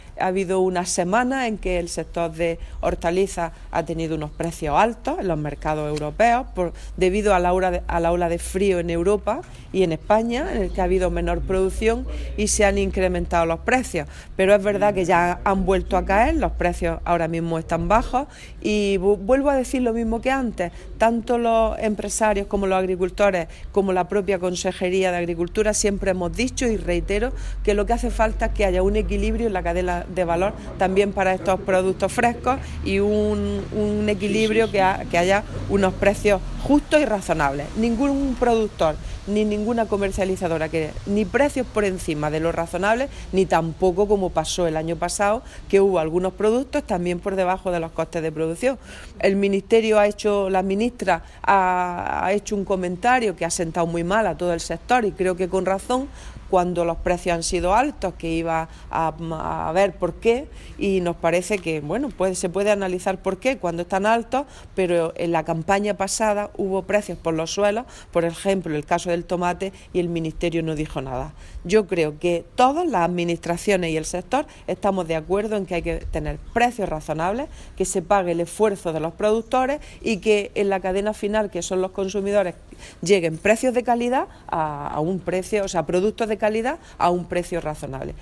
Declaraciones de Carmen Ortiz sobre el precio de productos hortofrutícolas